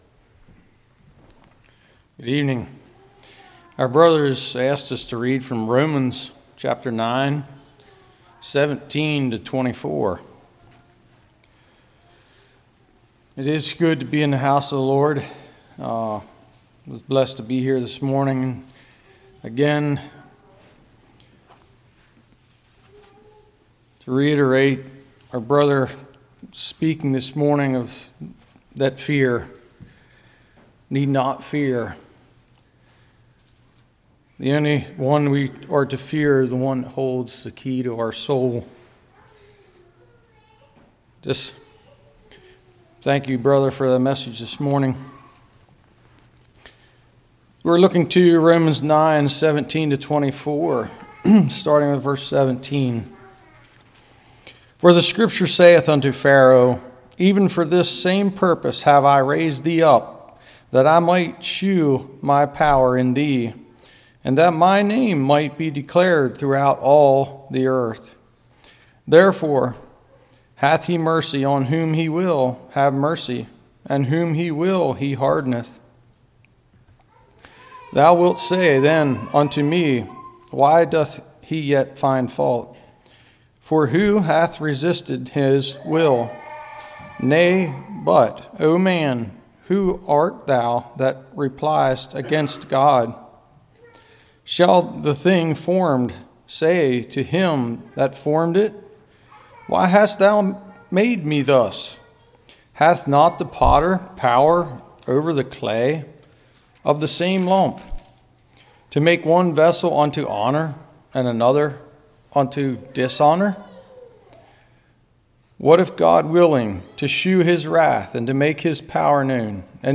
Romans 9:17-24 Service Type: Evening Resenting God’s design leads to self rejection.